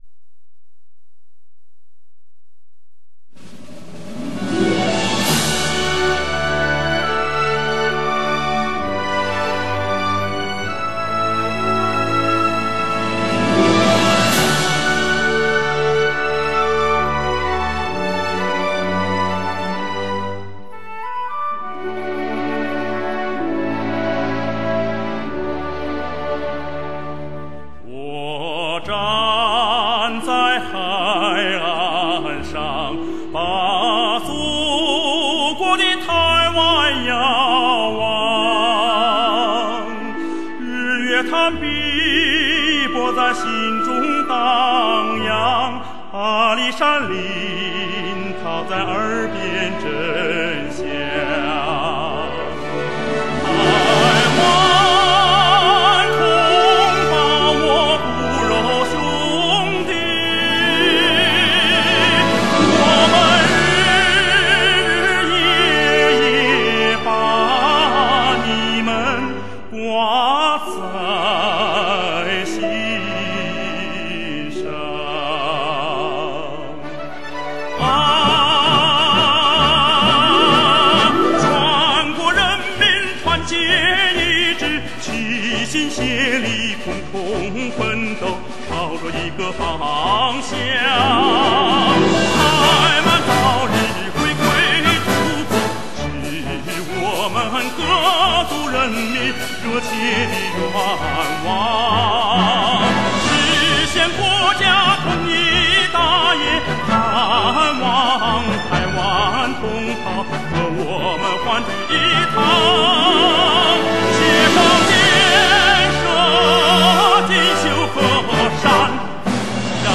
音乐类型:  民族歌曲
一份你应该拥有的艺术珍品，中国第一男高音。